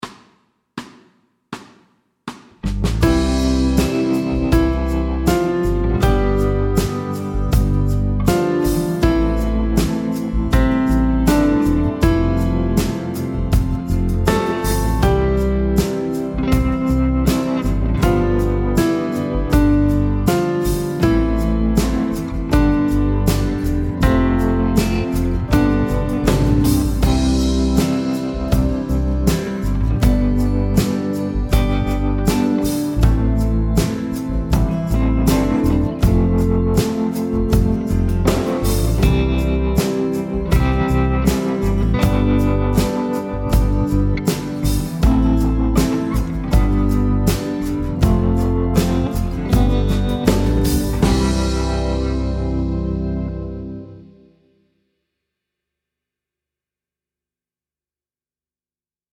Fast C instr (demo)
Rytmeværdier: 1/1-, 1/2-, og 1/4 noder og pauser.